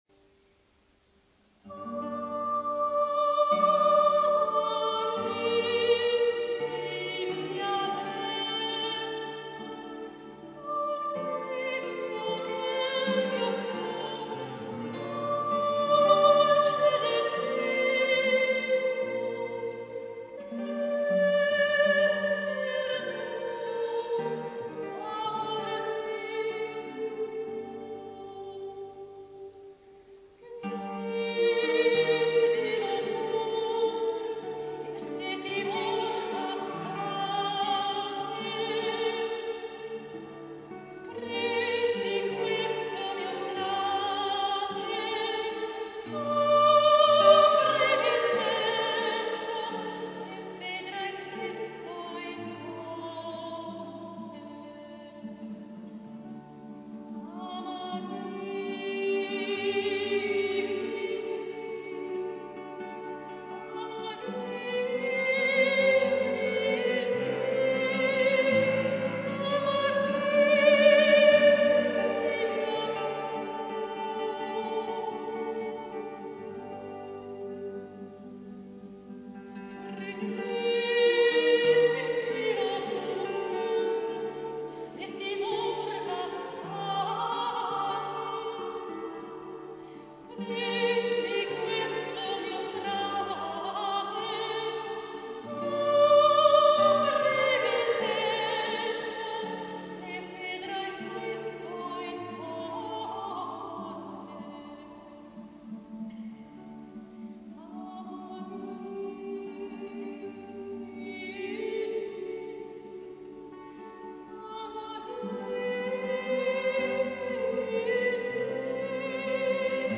Sopranistin